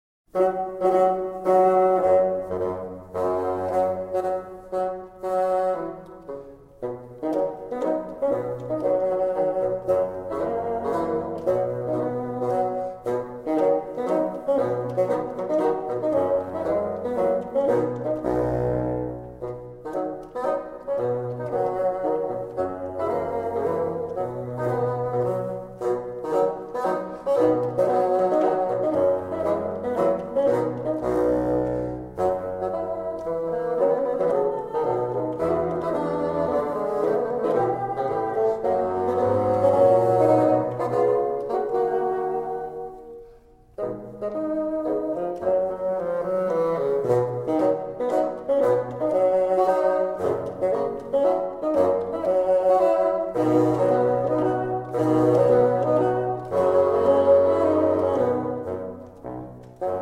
bassoon
Three Bassoon Trios